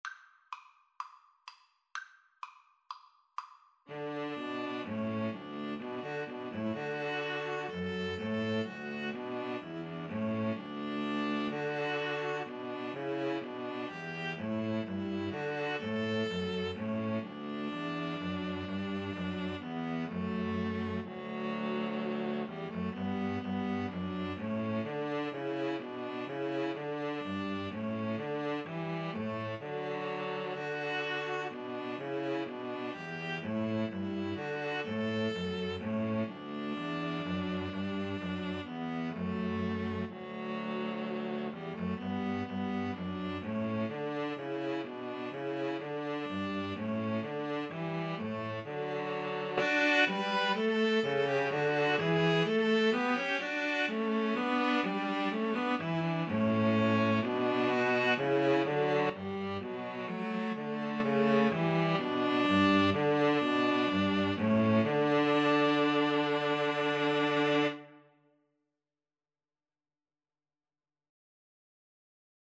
Allegro = 126 (View more music marked Allegro)